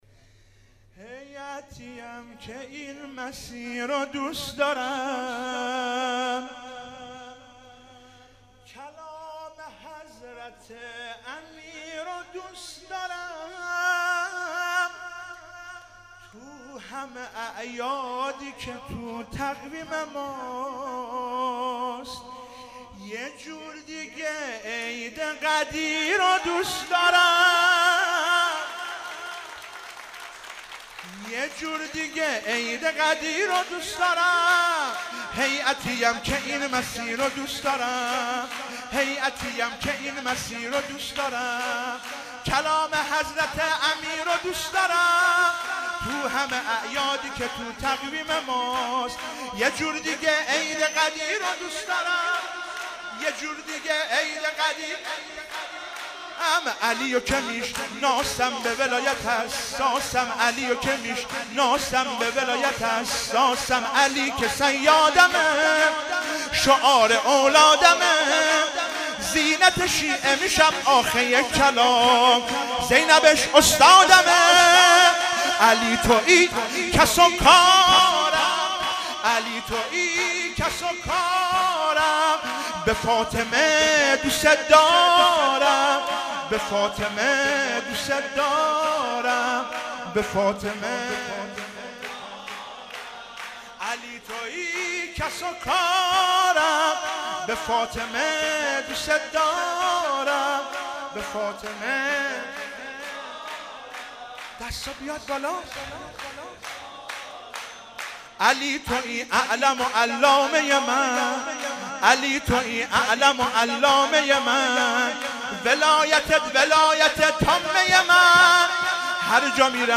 سرود مولودی